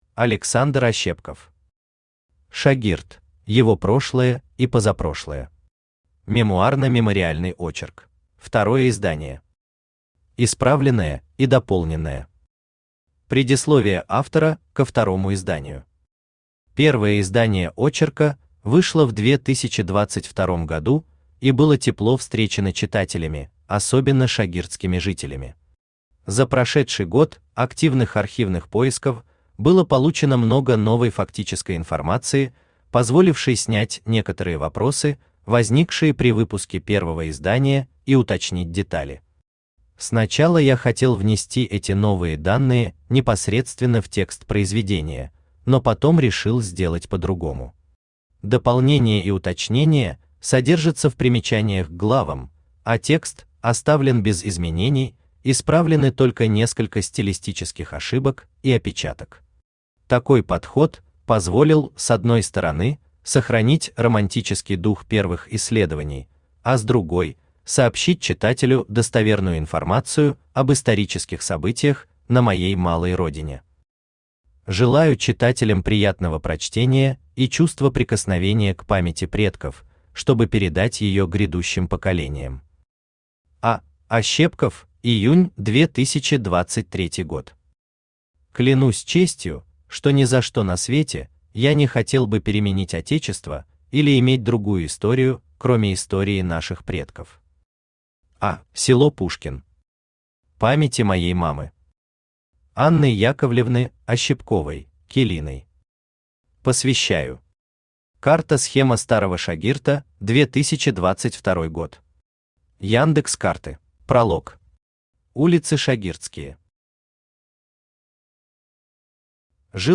Аудиокнига Шагирт: его прошлое и позапрошлое. Мемуарно-мемориальный очерк | Библиотека аудиокниг
Мемуарно-мемориальный очерк Автор Александр Юрьевич Ощепков Читает аудиокнигу Авточтец ЛитРес.